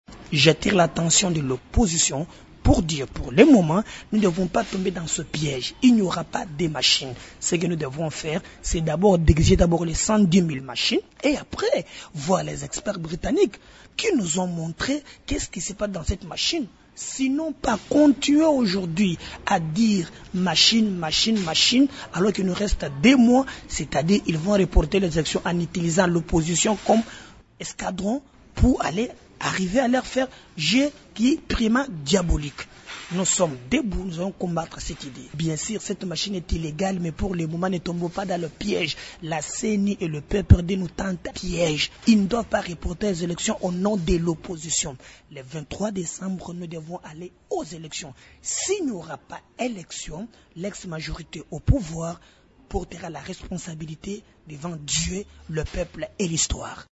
Il l’a déclaré ce mardi 23 octobre à Kinshasa au cours d’un point de presse.